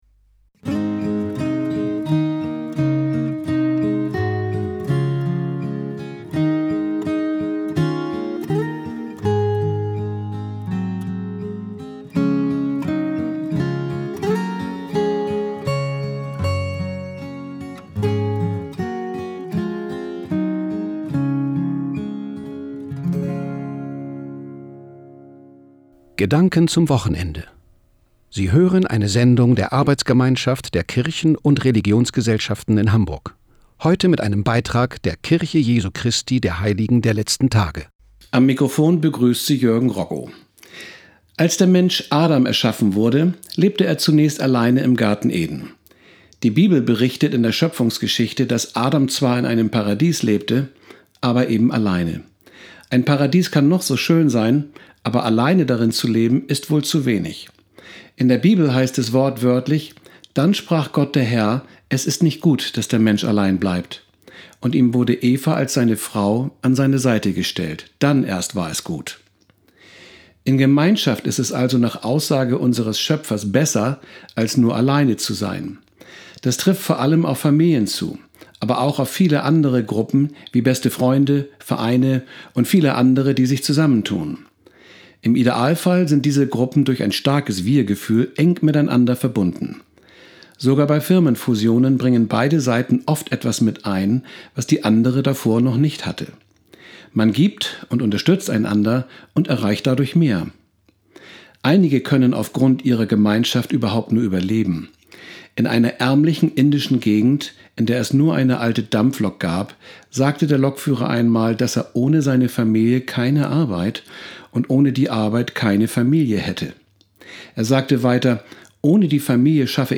Die am Samstag, den 2. September 2017, auf dem Hamburger Bürger- und Ausbildungskanal TIDE gesendete Botschaft der Kirche Jesu Christi der Heiligen der Letzten Tage zum Thema "Der Wert der Gemeinschaft" steht ab sofort als Podcast auf der Presseseite zur Verfügung.